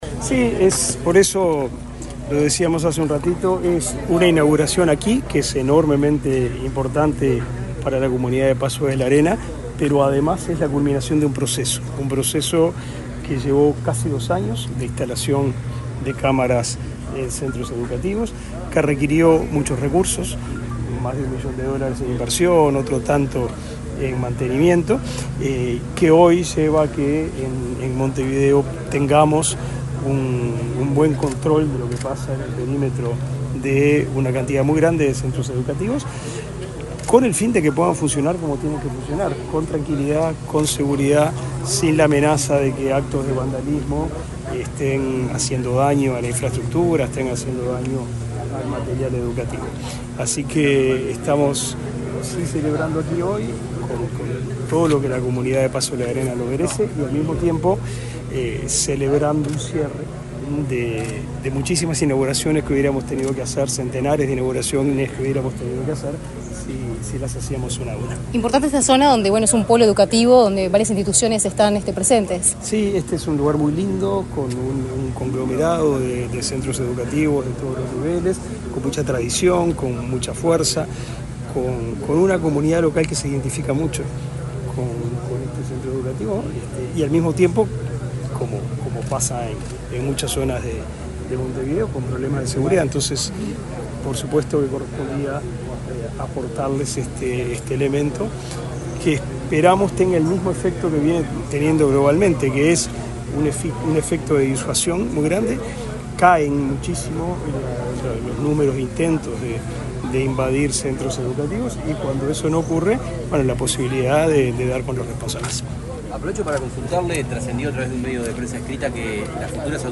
Declaraciones del ministro de Educación y Cultura, Pablo da Silveira
Declaraciones del ministro de Educación y Cultura, Pablo da Silveira 24/02/2025 Compartir Facebook X Copiar enlace WhatsApp LinkedIn Este lunes 24, el ministro de Educación y Cultura, Pablo da Silveira, dialogó con la prensa, luego de participar en la presentación de un sistema de cámaras de videovigilancia, enfocado a mejorar la seguridad de centros educativos.